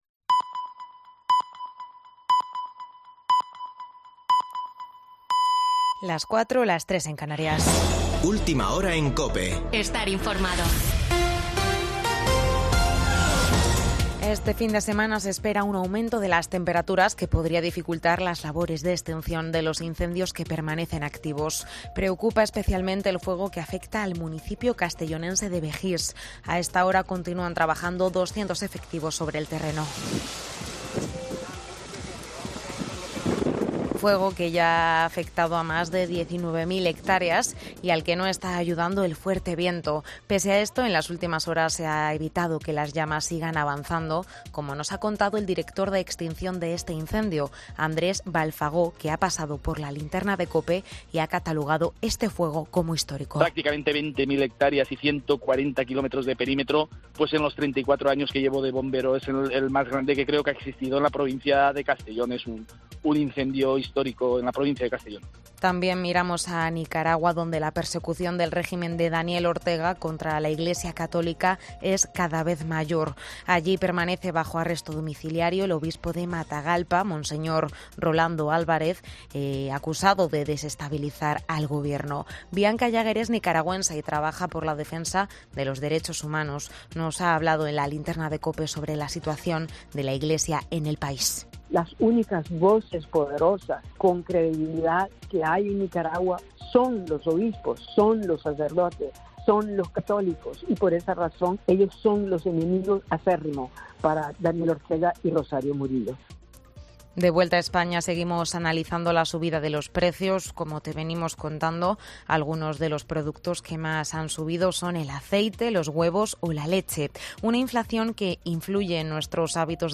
Boletín de noticias de COPE del 20 de agosto de 2022 a las 04.00 horas